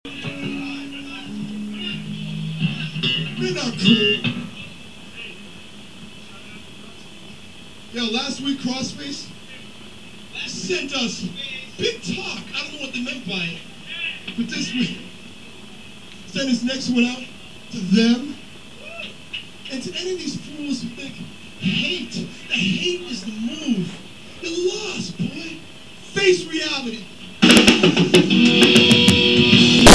at The Rat February 18, 1990